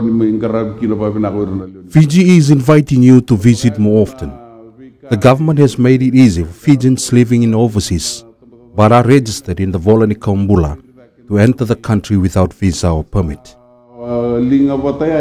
While speaking to Fijians, Vasu gave them an update on what his coalition government has done in the first six months after coming into power.